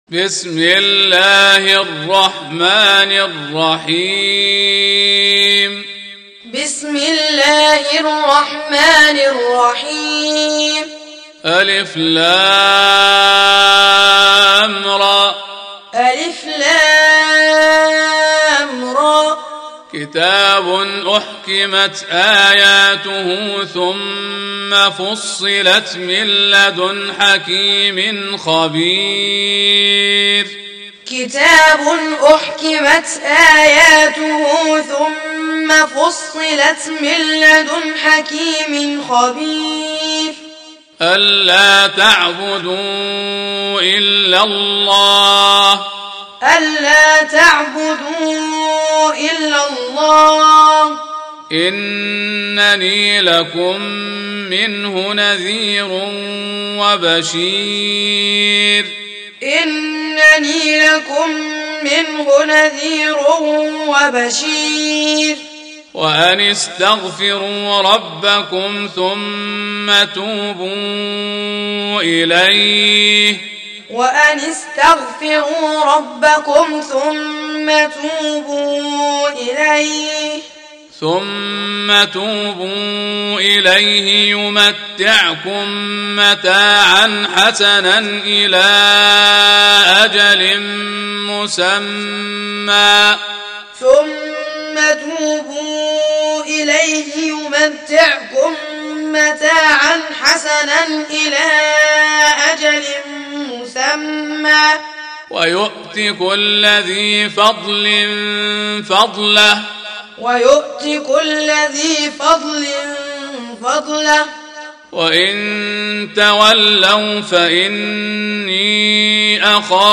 Surah Sequence تتابع السورة Download Surah حمّل السورة Reciting Muallamah Tutorial Audio for 11. Surah H�d سورة هود N.B *Surah Includes Al-Basmalah Reciters Sequents تتابع التلاوات Reciters Repeats تكرار التلاوات